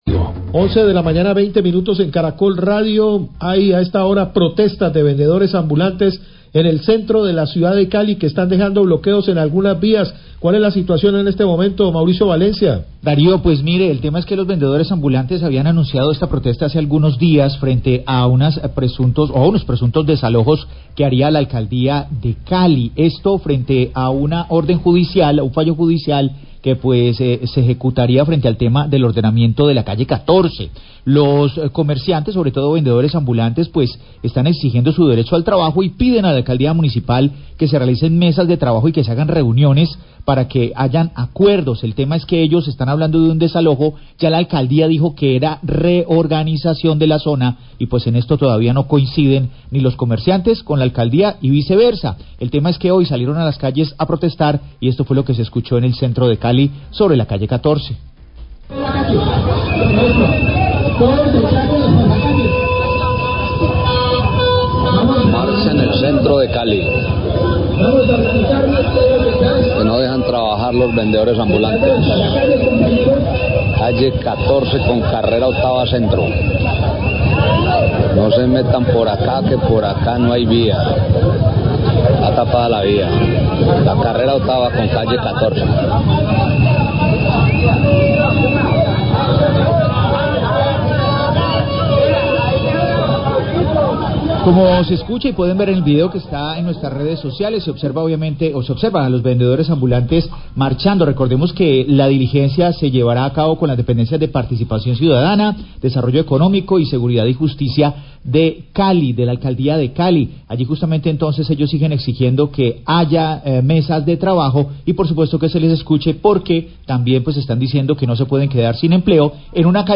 Radio
En entrevista con la Concejal Claudia Jaramillo, es tocado el tema de la recolección de basuras en la ciudad de Buga, donde también mencionan los altos costos en los recibos, que se ven afectados por el cobro de la recolección y no tanto por el cobro de la energía.